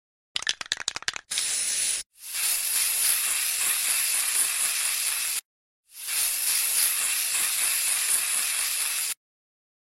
Tiếng Phun, Xịt hơi xịt xịt xì xì…
Thể loại: Tiếng động
tieng-phun-xit-hoi-xit-xit-xi-xi-www_tiengdong_com.mp3